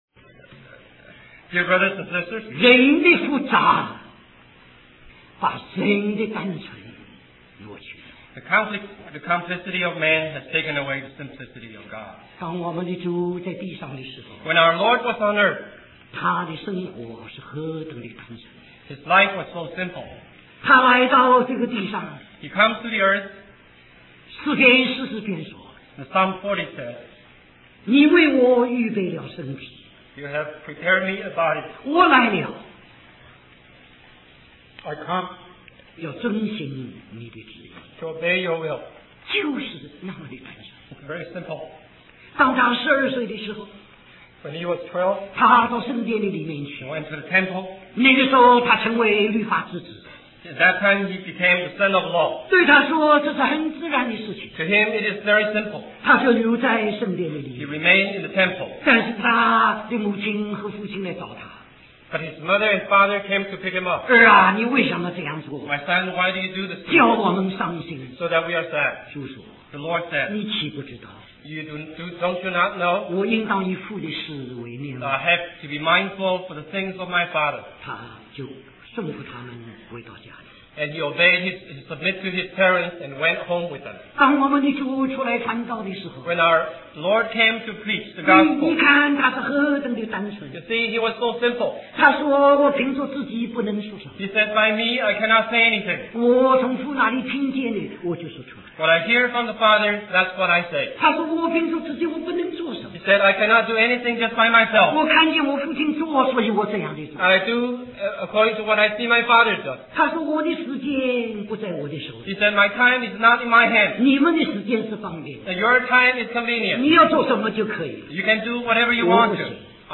Conference at Bible Institute of Los Angeles